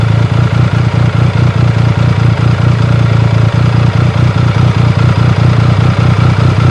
Motor 2.wav